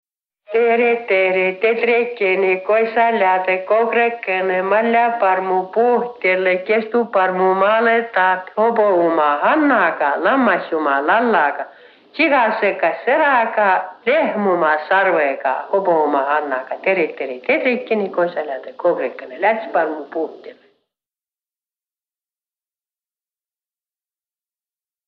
Laul “Tere-tere, tedrekene”